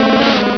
Cri d'Ortide dans Pokémon Rubis et Saphir.